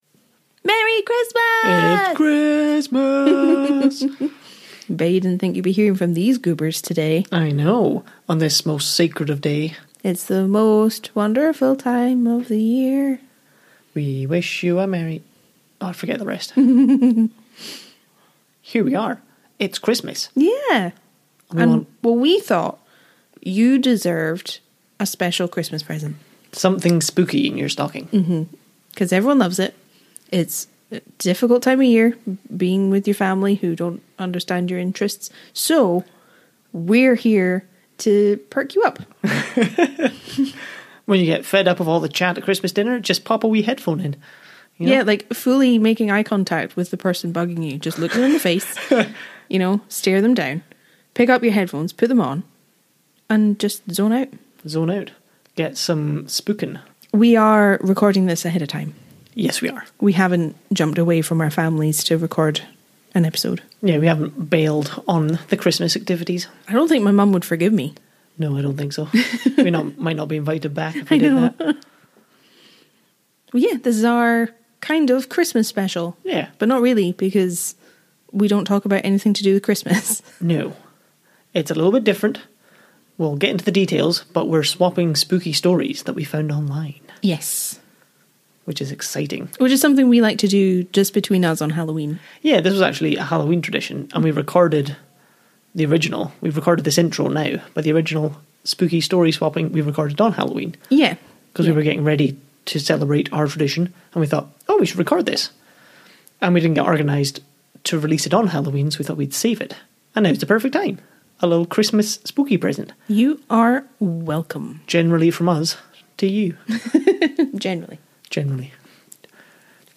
It's actually something we recorded on Halloween where we found 5 spooky stories from reddit and read them to each other.